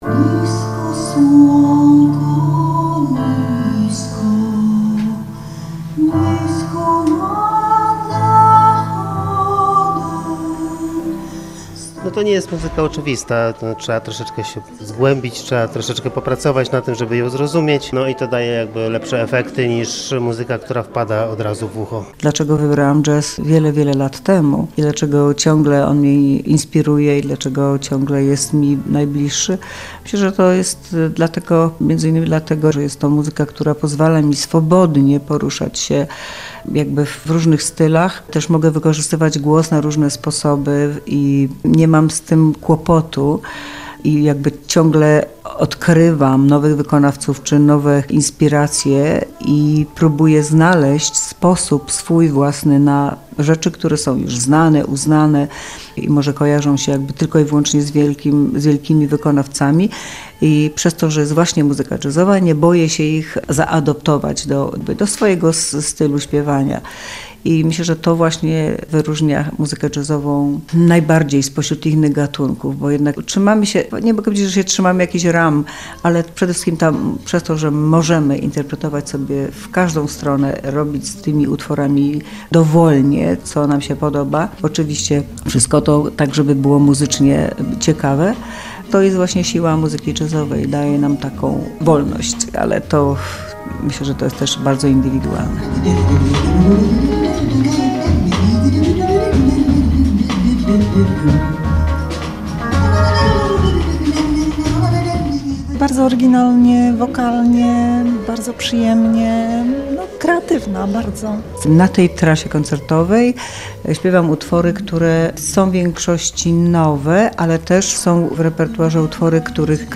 W Teatrze Starym w Lublinie odbył się koncert zespołu Grażyny Auguścik Chicago Quintet.
Wykonali program „On the Way”, oparty na jazzie i inspiracjach muzyką polską oraz klasyczną.
Grażyna Auguścik to polska wokalistka jazzowa, która od wielu lat mieszka i pracuje w Stanach Zjednoczonych.
Jej śpiew jest zróżnicowany i opiera się na improwizacji.